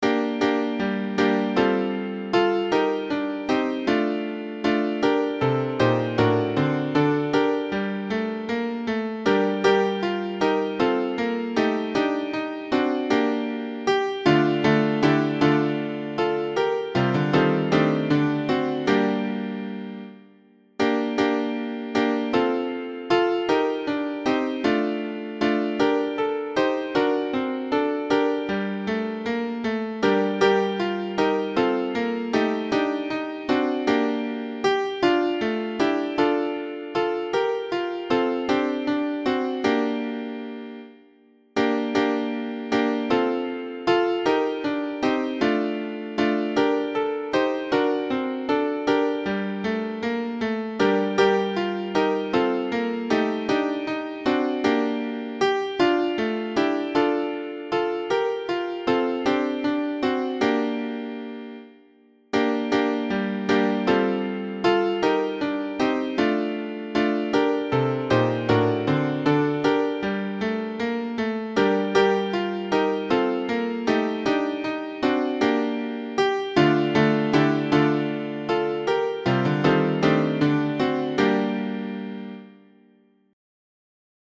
tutti
A-Bethleem-Jesus-est-ne-tutti.mp3